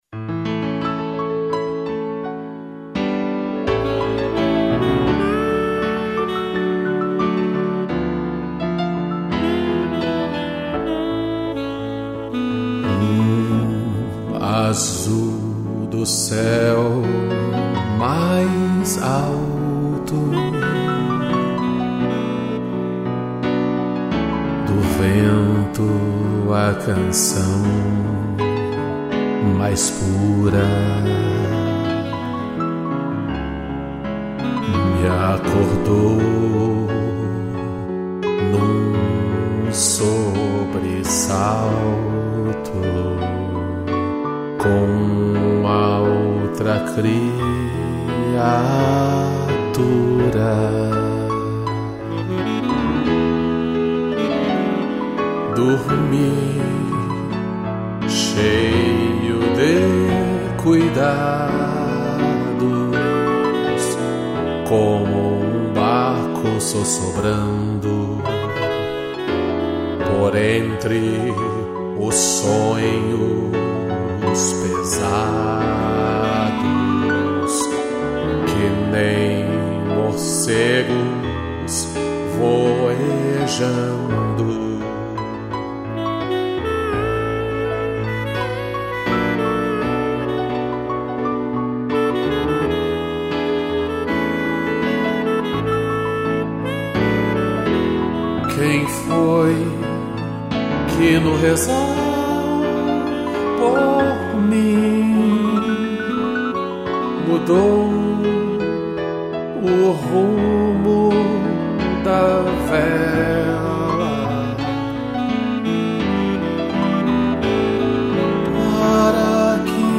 2 pianos e sax